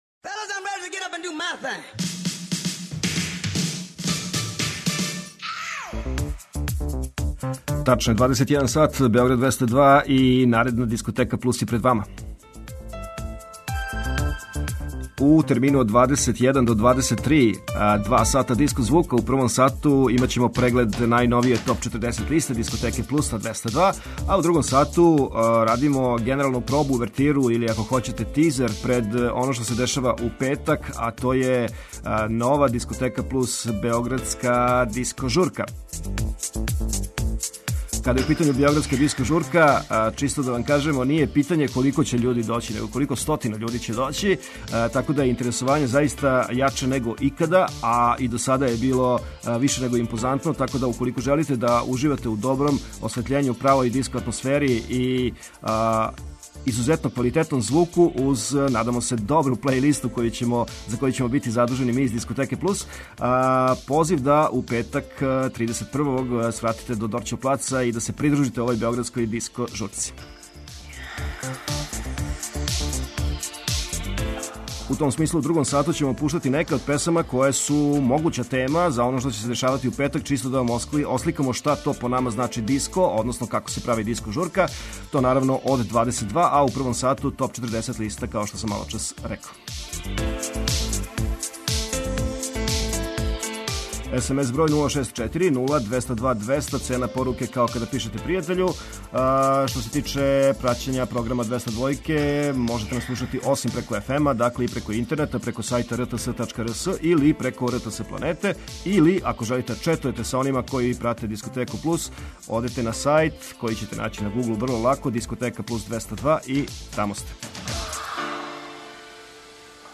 Eмисија посвећена најновијој и оригиналној диско музици у широком смислу.
Заступљени су сви стилски утицаји других музичких праваца - фанк, соул, РнБ, итало-диско, денс, поп. Сваке среде се представља најновија, актуелна, Топ 40 листа уз непосредан контакт са слушаоцима и пуно позитивне енергије.